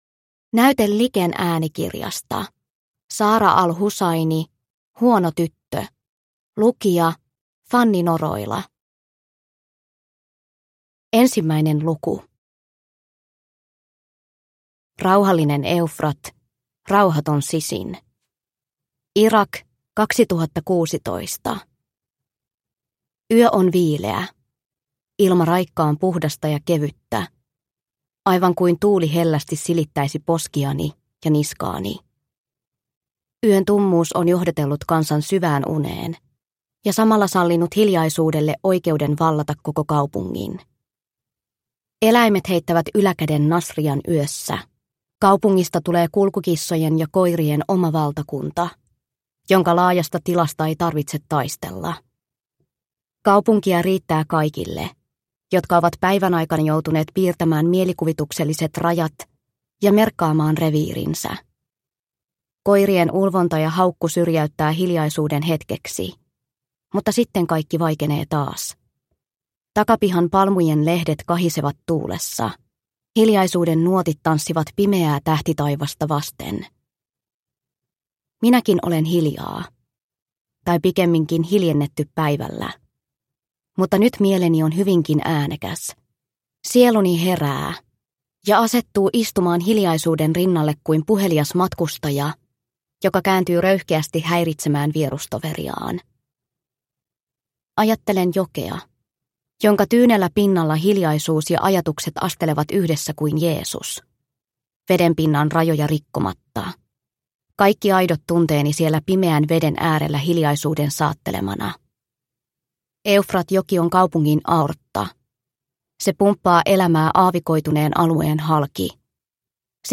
Huono tyttö – Ljudbok – Laddas ner